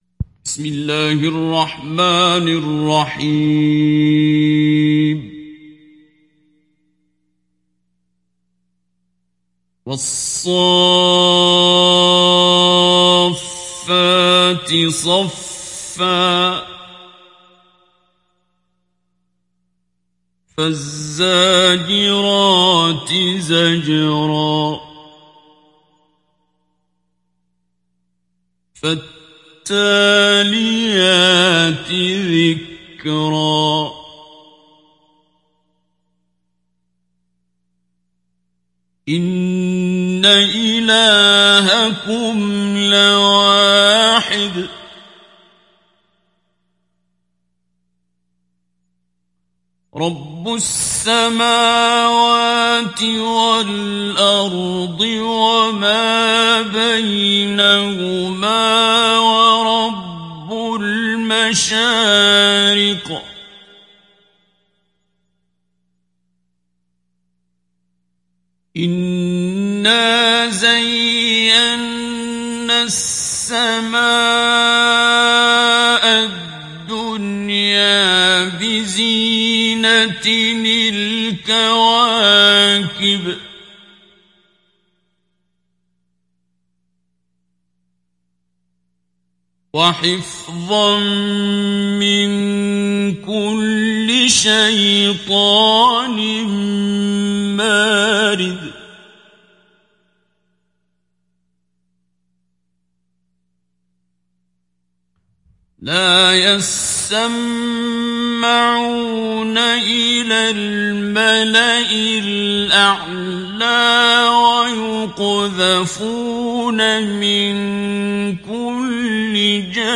Hafs an Asim
Mujawwad